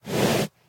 mob / horse / breathe1.ogg
breathe1.ogg